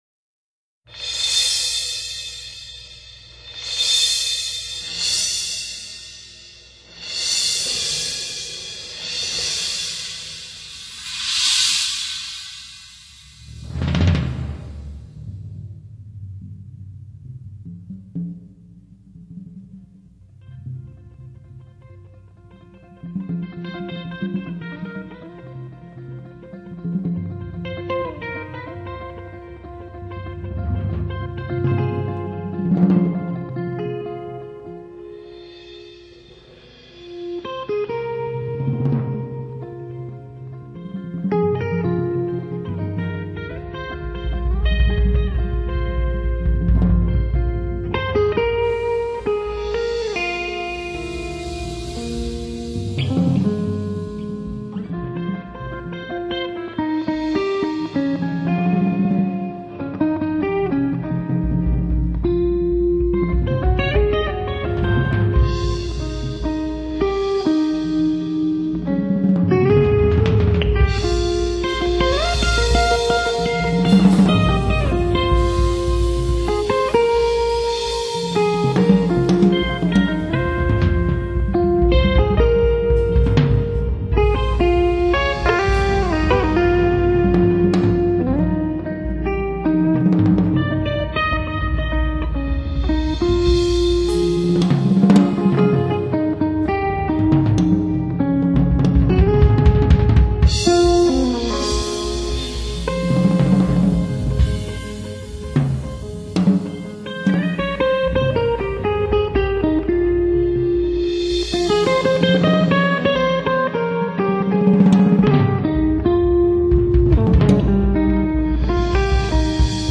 音乐类型：爵士